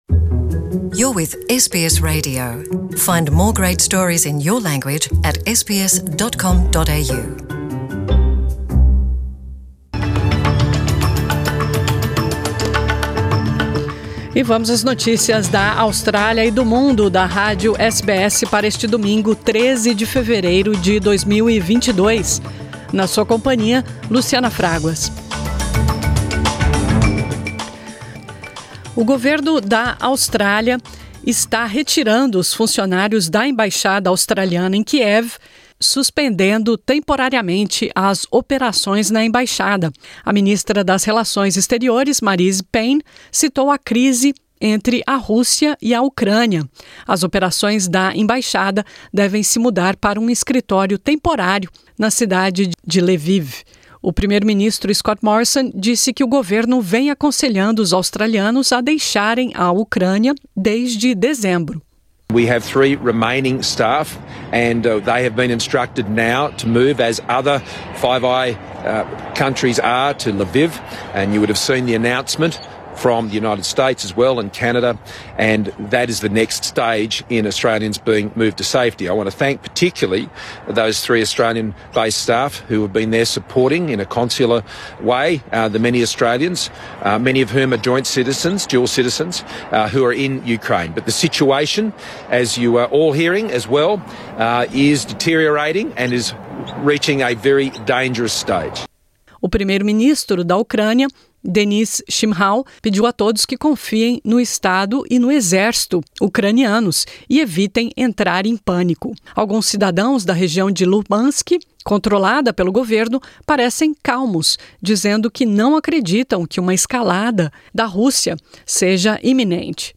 O Kremlin pediu que o presidente brasileiro Jair Bolsonaro faça cinco testes de Covid antes de se encontrar com Vladimir Putin. Bolsonaro anunciou que vai com uma comitiva à Moscou tratar de assuntos que interessam ao Brasil. Acompanhe as principais notícias da Austrália e do mundo da Rádio SBS para este domingo 13 de fevereiro.